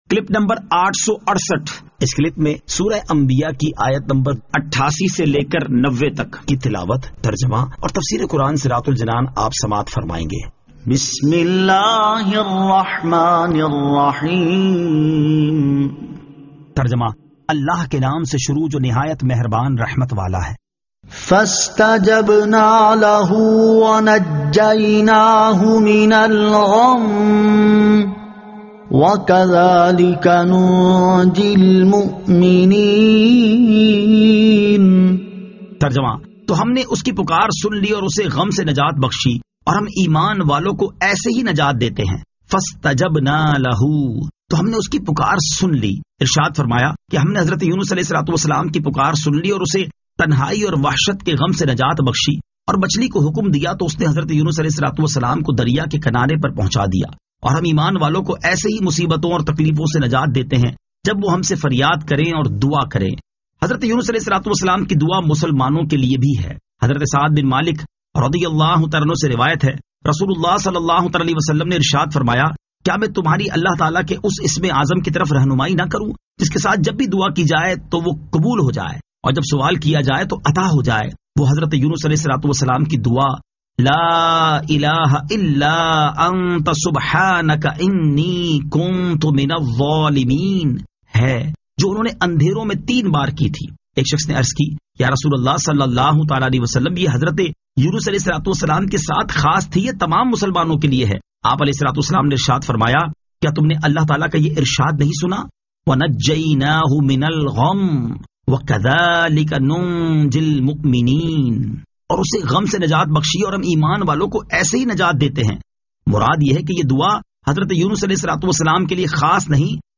Surah Al-Anbiya 88 To 90 Tilawat , Tarjama , Tafseer